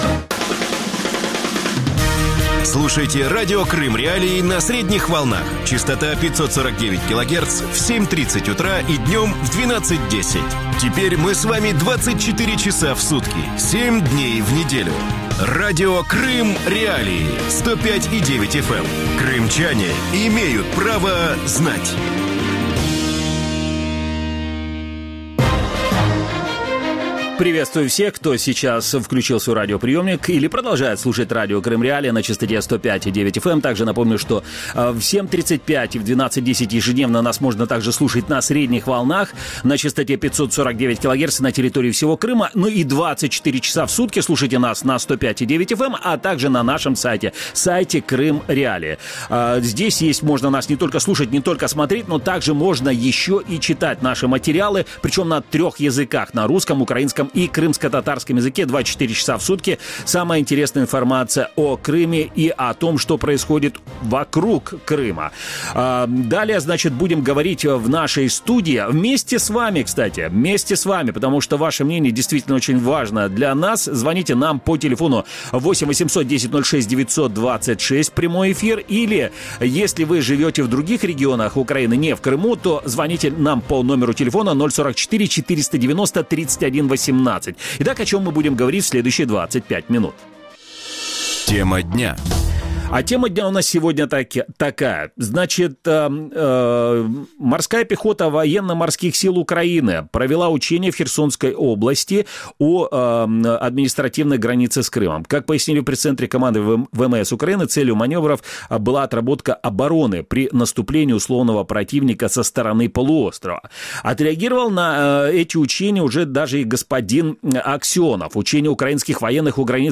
Гости эфира
военный эксперт